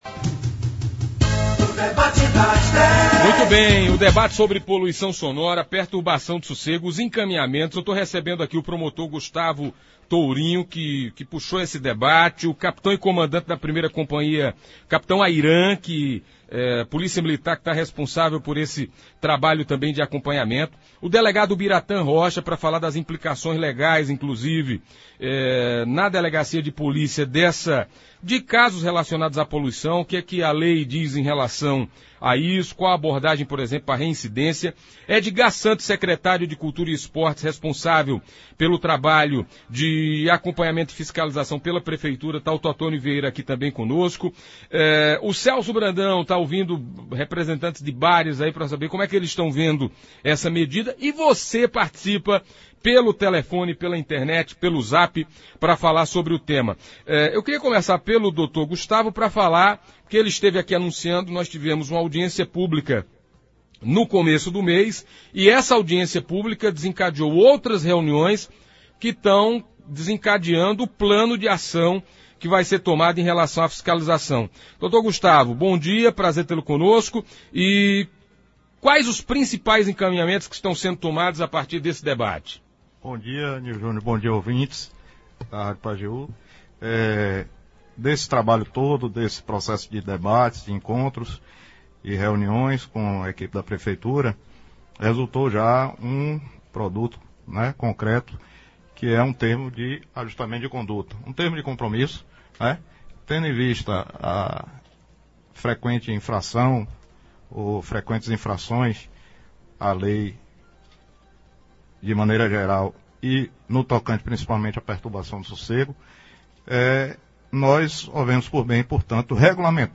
Ouça abaixo a integra do debate: